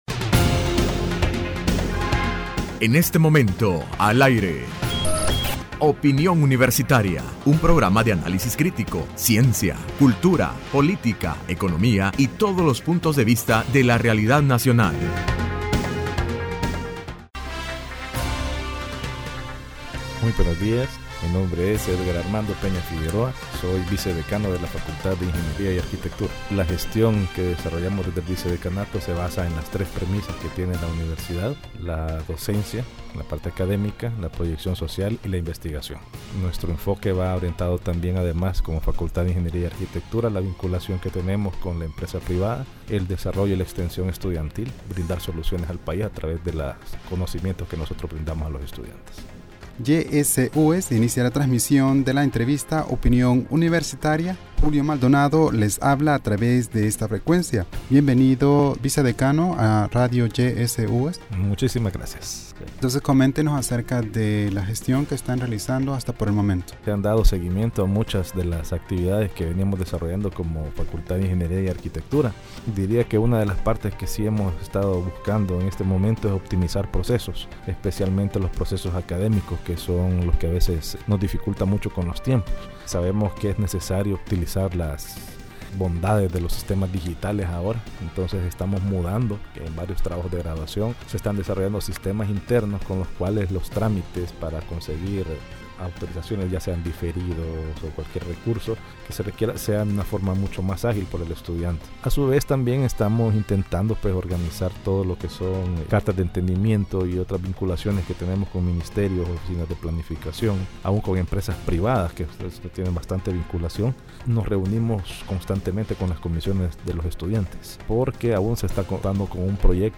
Entrevista Opinión Universitaria (11 de Julio 2016) : Plan de trabajo 2015-2019,Facultad de Ingeniería y Arquitectura.